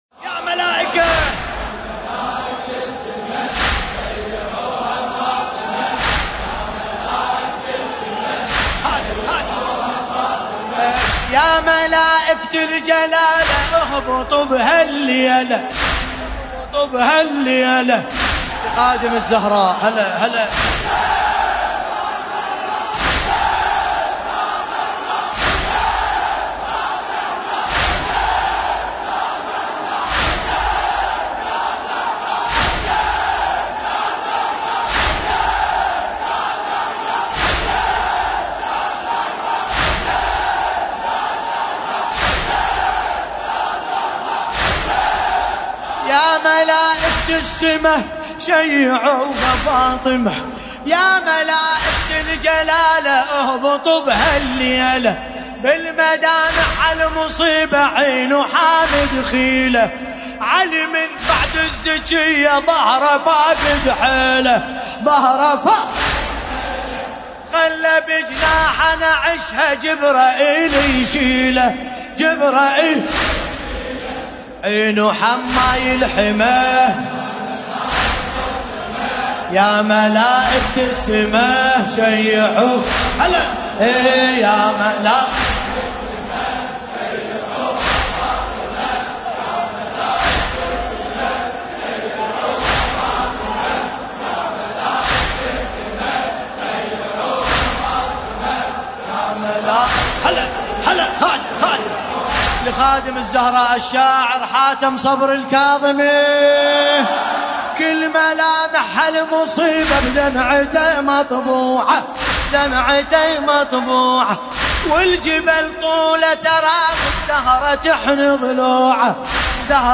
المناسبة : الليالي الفاطمية 1440